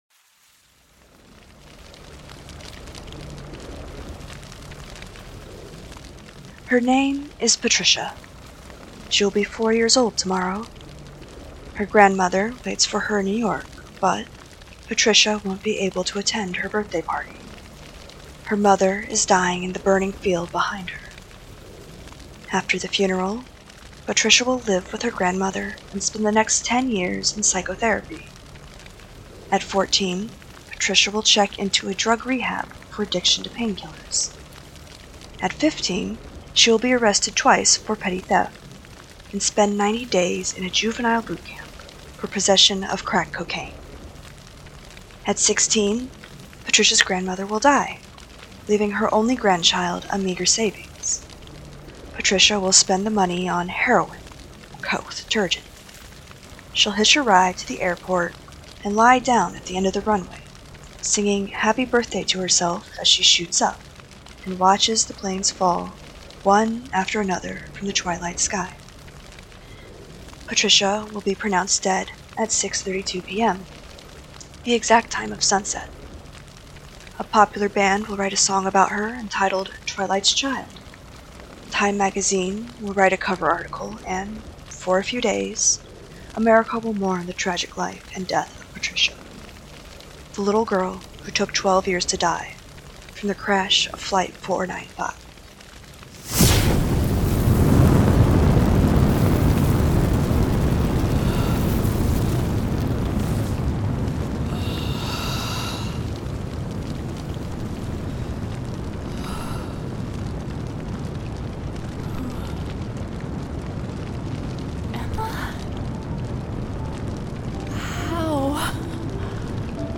Strangers In Paradise – The Audio Drama – Book 8 – My Other Life – Episode 4 – Two True Freaks